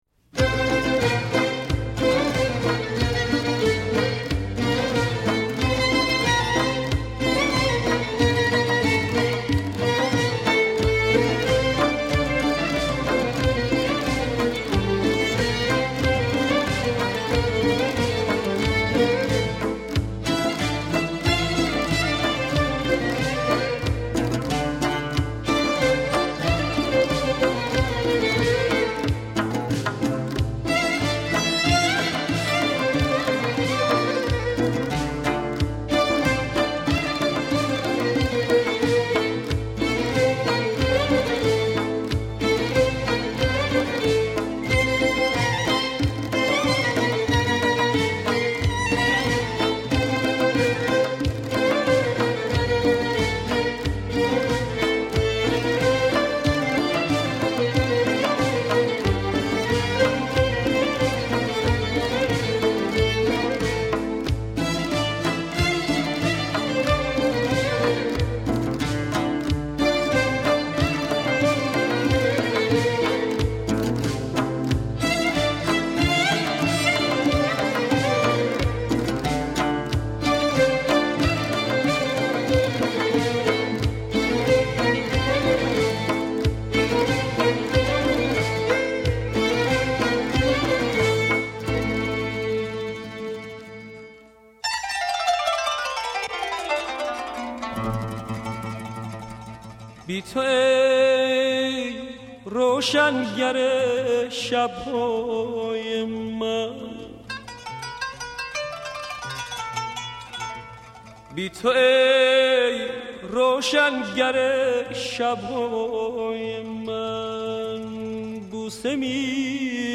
در سبک سنتی و پاپ